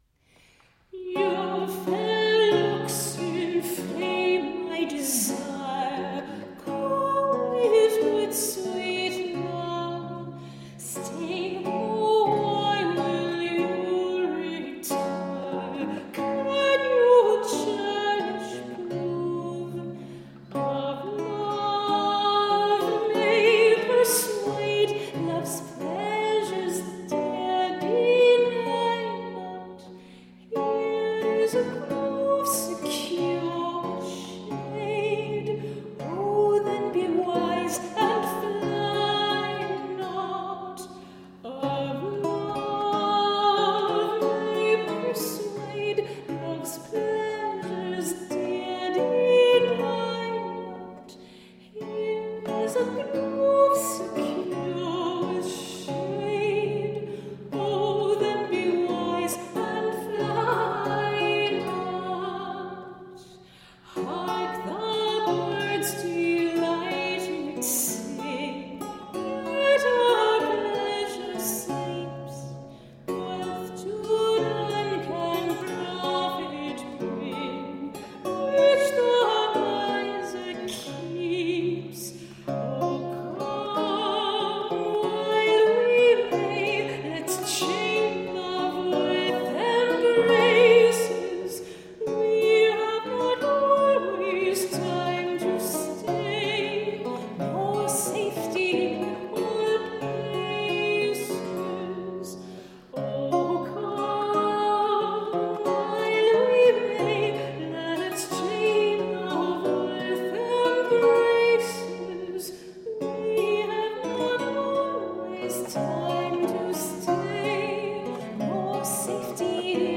A feast of baroque lute.
Classical, Baroque